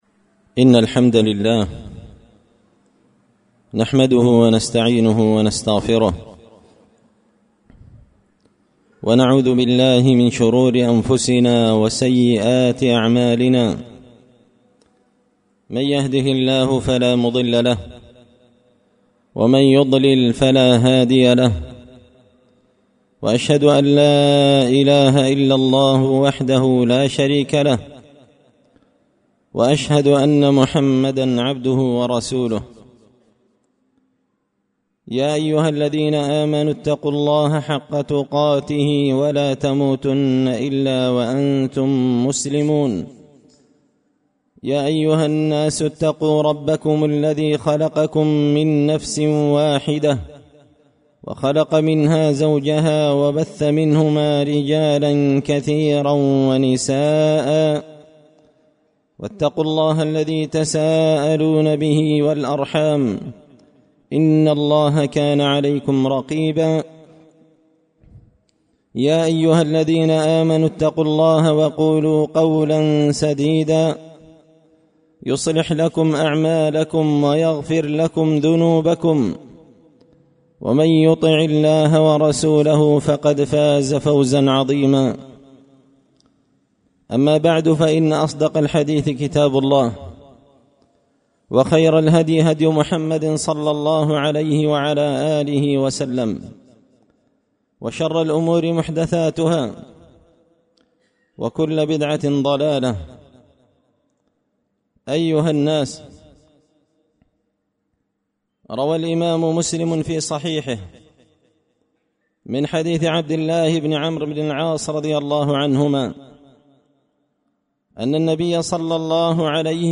خطبة جمعة بعنوان – النصيحة المختارة في حكم قيادة المرأة للسيارة
دار الحديث بمسجد الفرقان ـ قشن ـ المهرة ـ اليمن